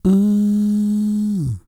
E-CROON P324.wav